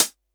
Tr8 Closed Hat 01.wav